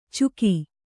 ♪ cuki